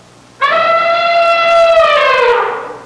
Bull Elephant Sound Effect
Download a high-quality bull elephant sound effect.
bull-elephant-1.mp3